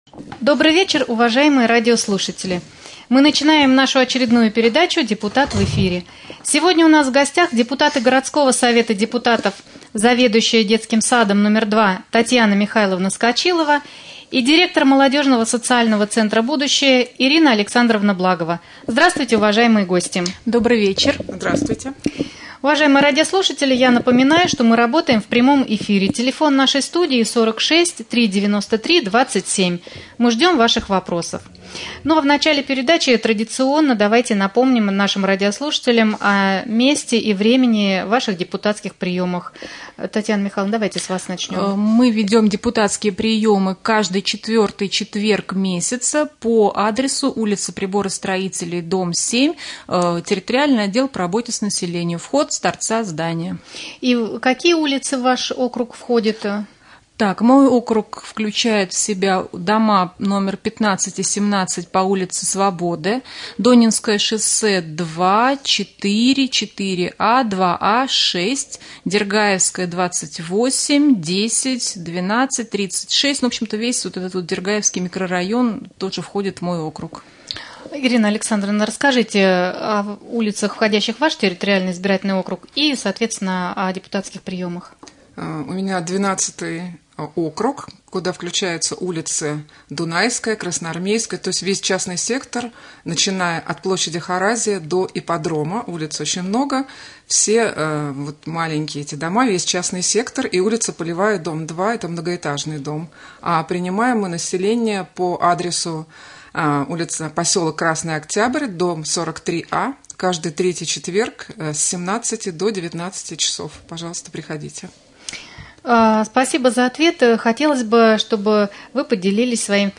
Прямой эфир. Депутаты Совета депутатов городского поселения Раменское Ирина Александровна Благова и Татьяна Михайловна Скочилова.